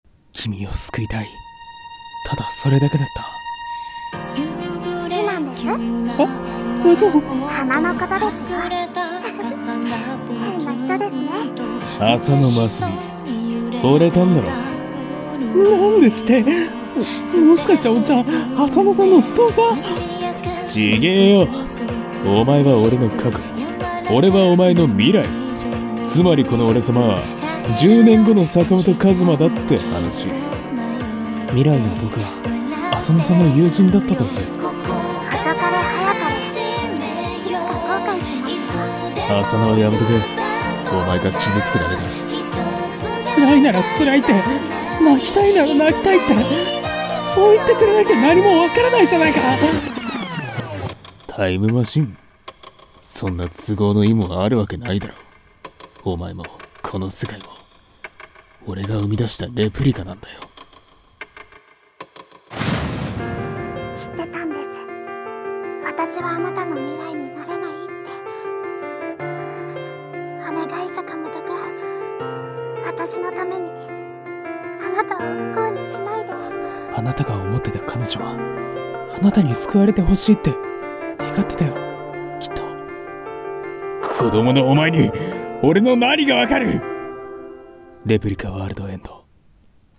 映画予告風声劇【Re:PLICA WORLD END】